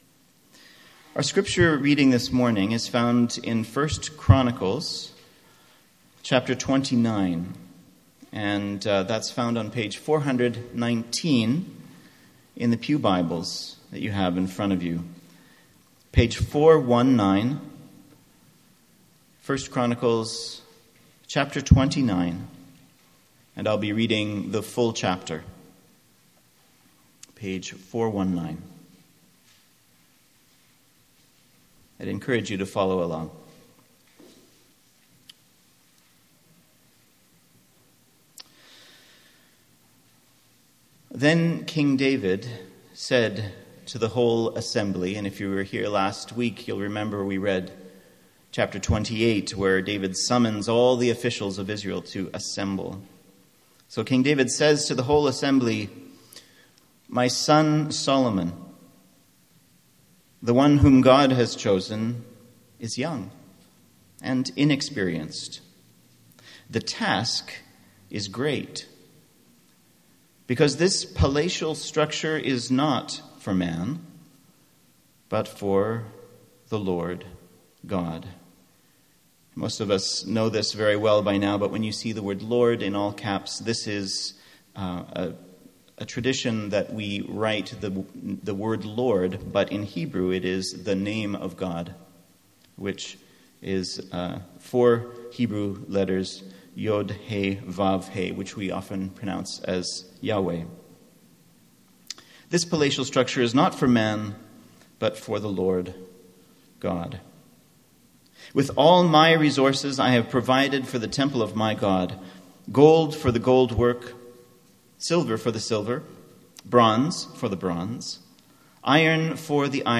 MP3 File Size: 32.1 MB Listen to Sermon: Download/Play Sermon MP3